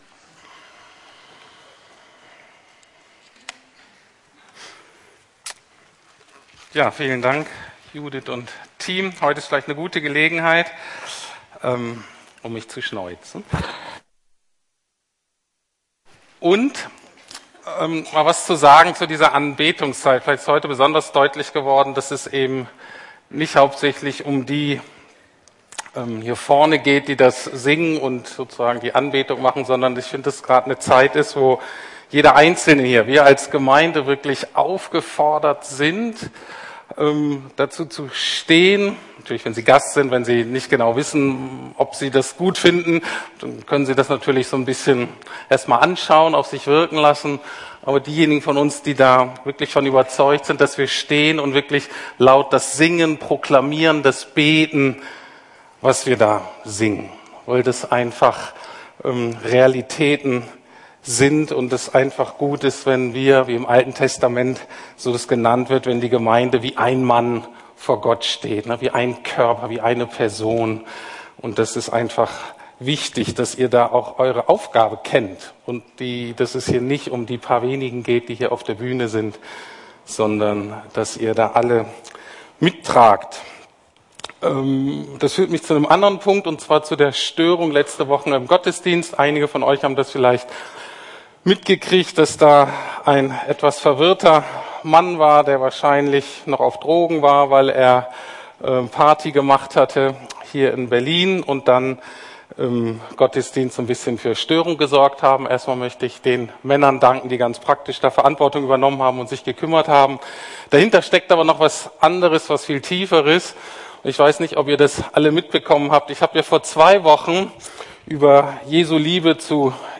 Liebe, was Jesus liebt (3): selbstbewusste Selbstaufgabe ~ Predigten der LUKAS GEMEINDE Podcast